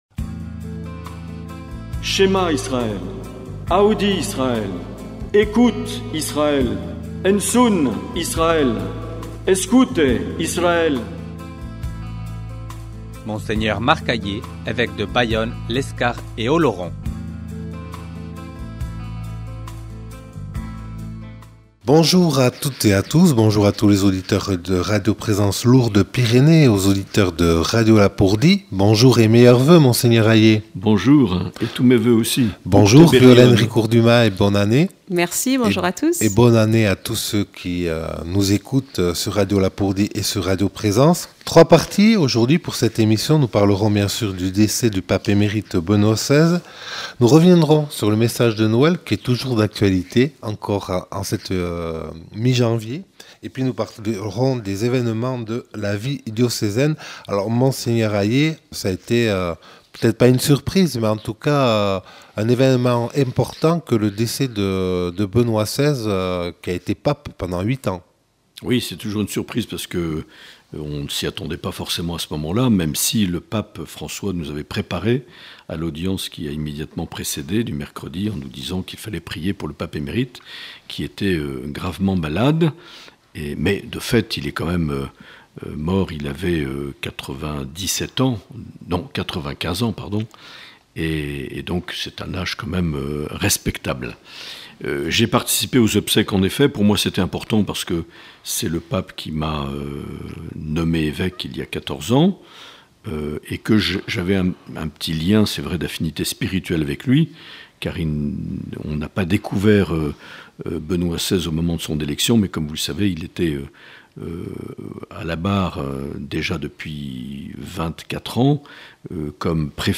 L’entretien avec Mgr Aillet - Janvier 2023